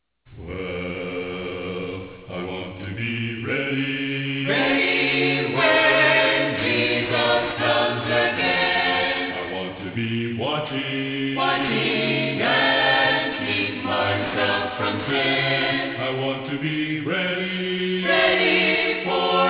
Soprano
Altos
Tenor
Bass